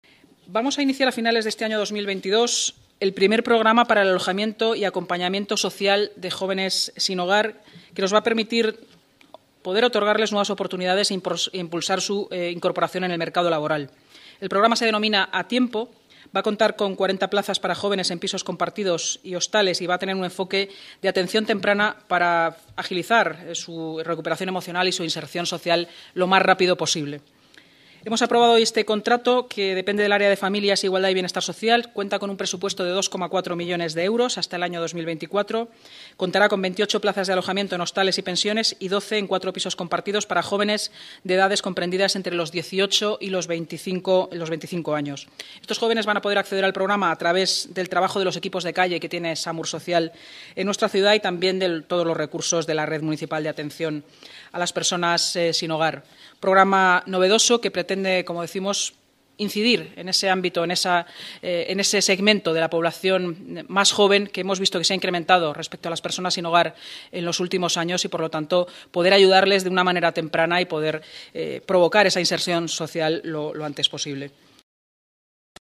Nueva ventana:Declaraciones de la portavoz municipal, Inmaculada Sanz, esta mañana, tras la celebración de la Junta de Gobierno: